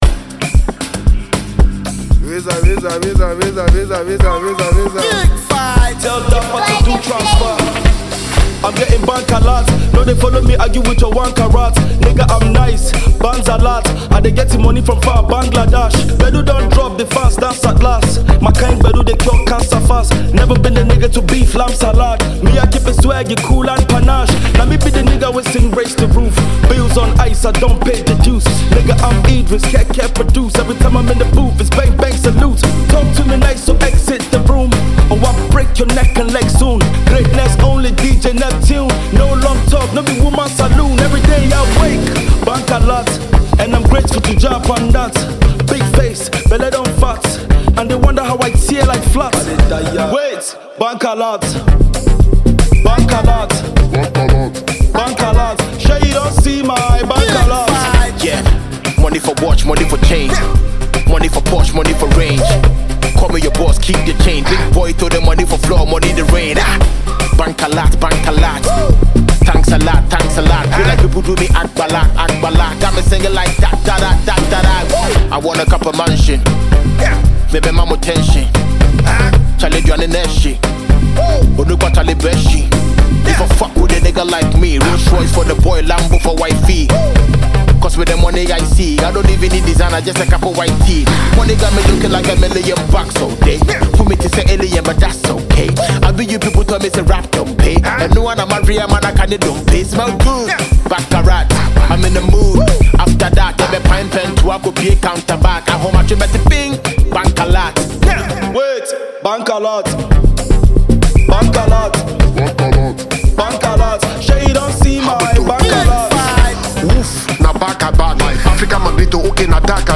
Renowned Nigerian rapper and hip-hop artist